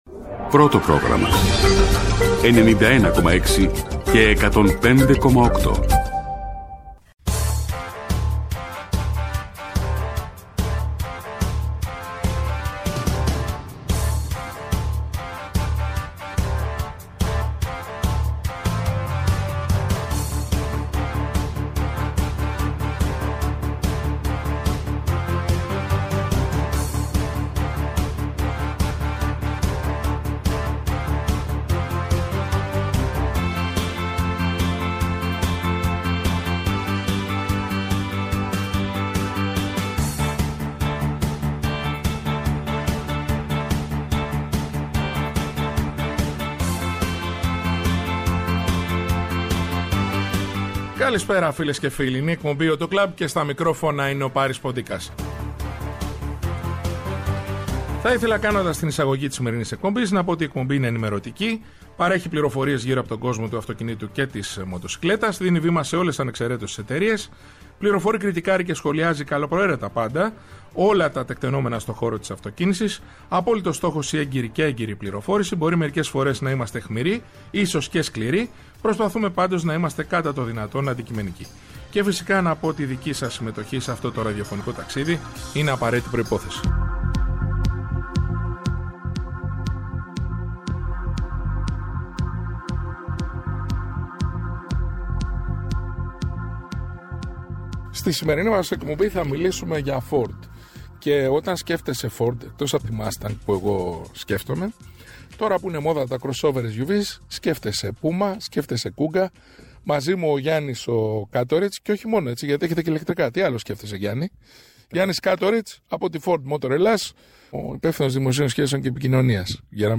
Ταξιδέψτε με το Auto Club με αποσκευές την πληροφορία, την καλή μουσική και το χιούμορ για να μάθετε όλες τις απαντήσεις και τα τελευταία νέα από τον κόσμο της αυτοκίνησης. ΕΡΤNEWS RADIO